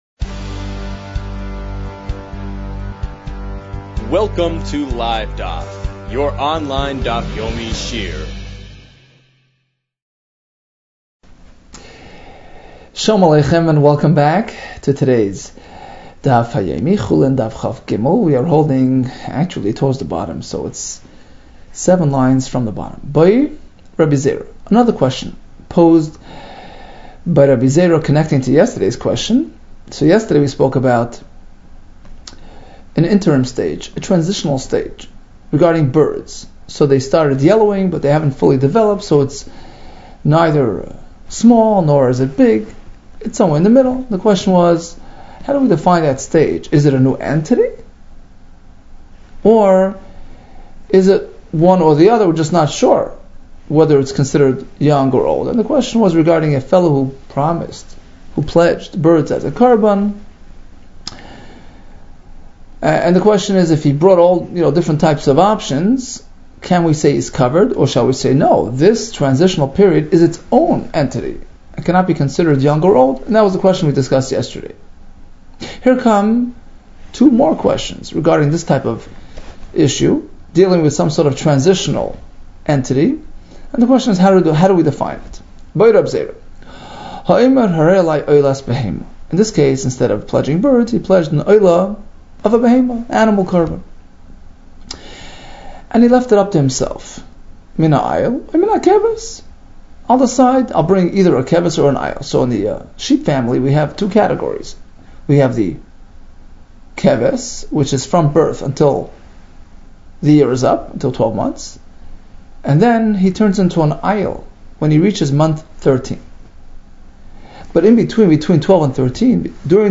Chulin 22 - חולין כב | Daf Yomi Online Shiur | Livedaf